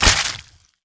atk_bite_medium_003.wav